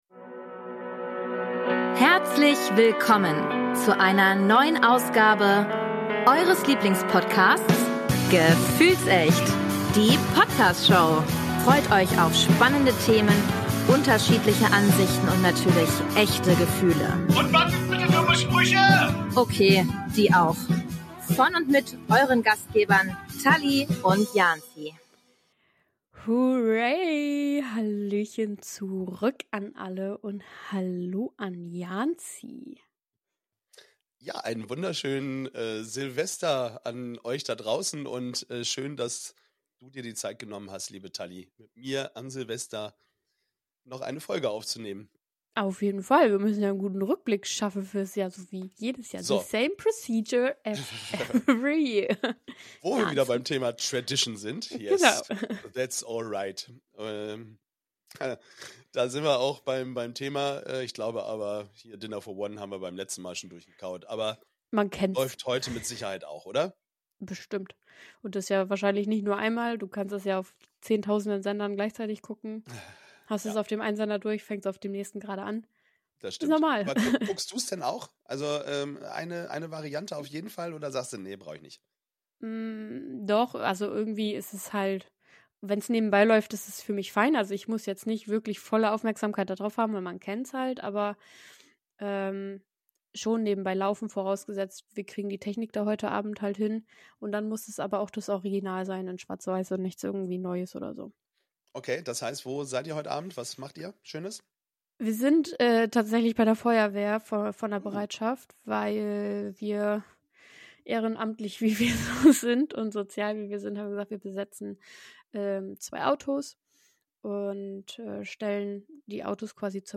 Und ein paar Stimmen von Ihnen hören wir per Telefon zugeschaltet!